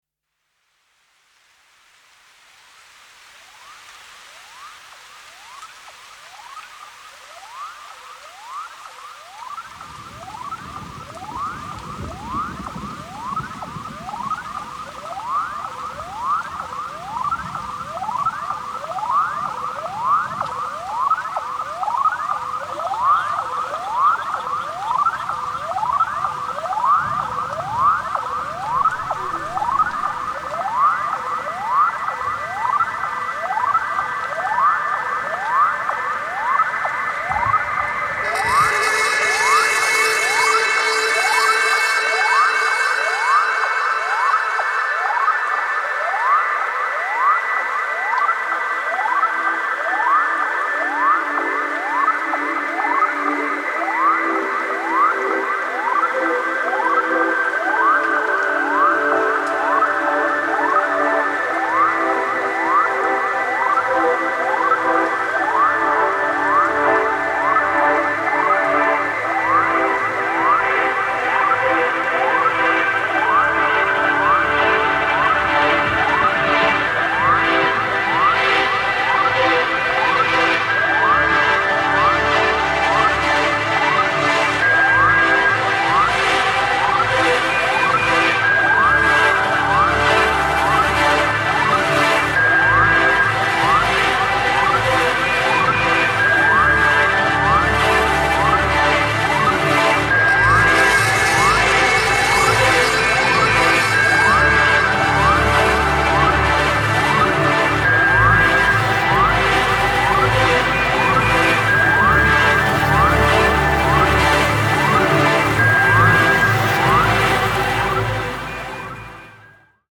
Unfolding over 4 steppy tracks and an Ambient closer
House Ambient Breaks